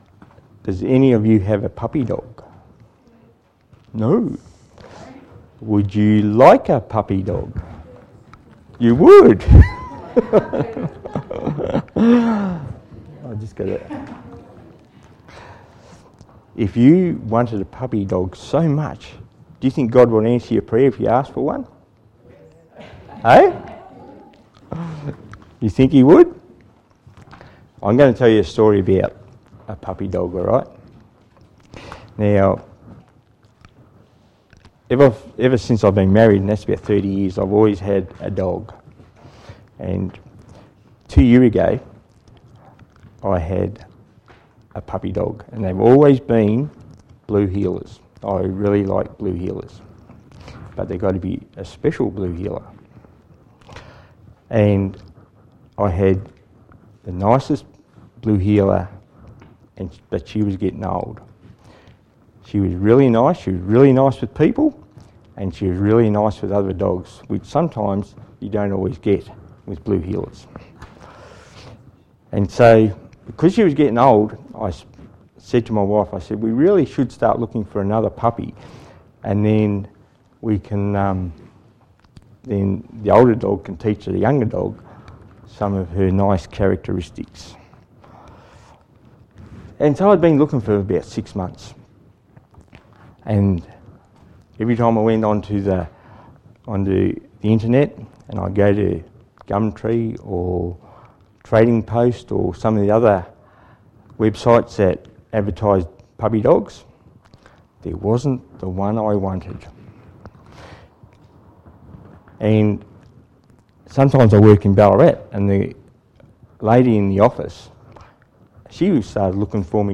Children's Stories